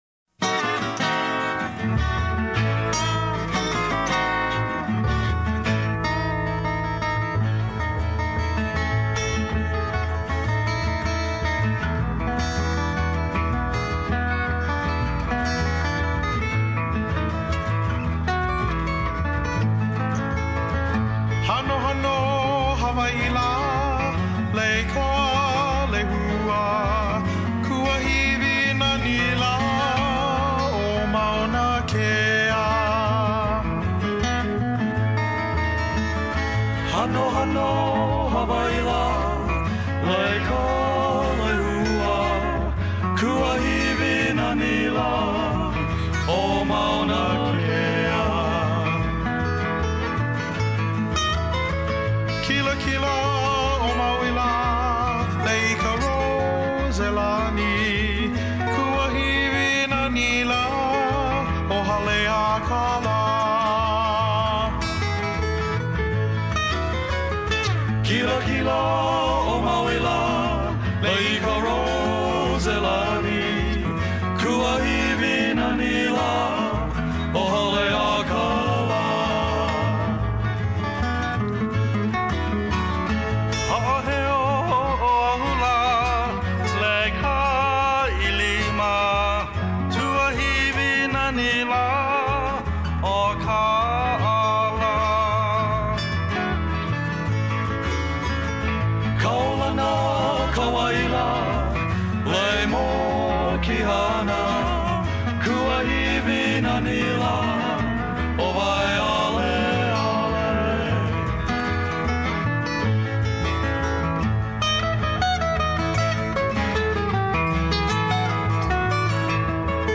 Music up front all the way!
Office of Hawaiian Affairs at-large trustee Rowena Akana joined us by phone right off the plane from Washington D.C. She was there to lobby for the Akaka Bill. Listen in to find out her assessment of the bill’s chances in the House and Senate this time around. Trustee Akana also shared her views of the past Hawai’i legislative session and current challenges for OHA.